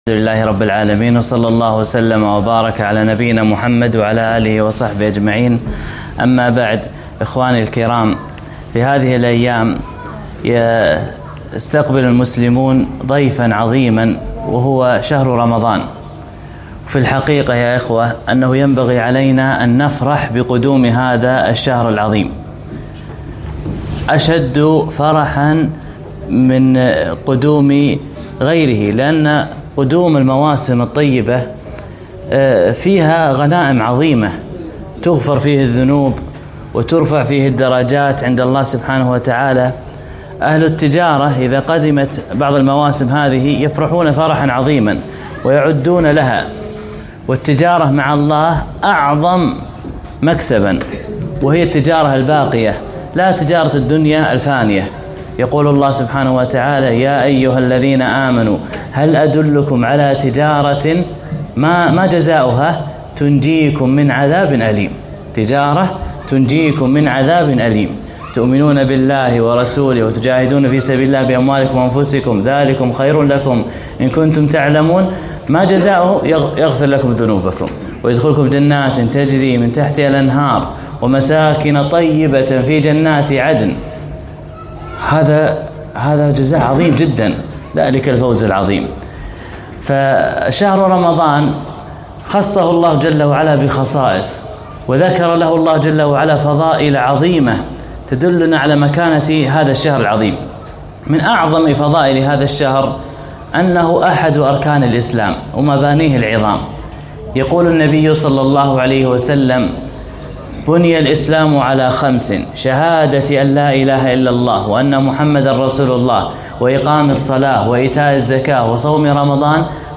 محاضرة - فضائل الصيام